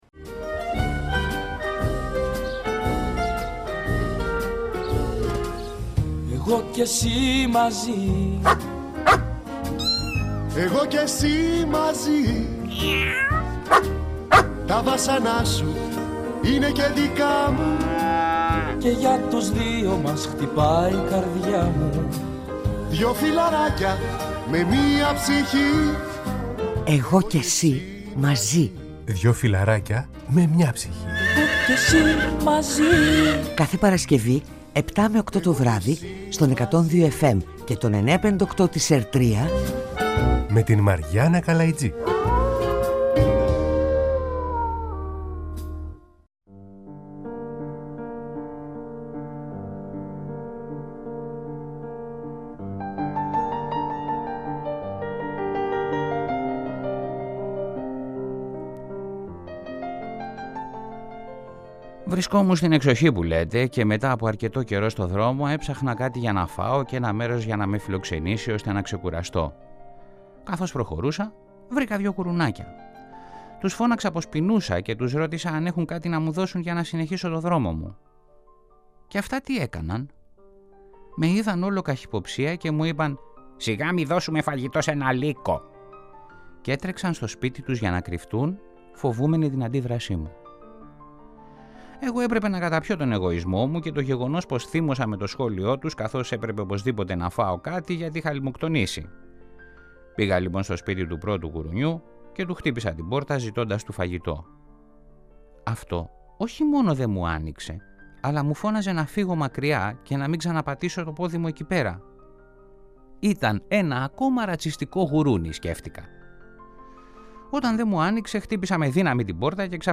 Μαζί μας στο στούντιο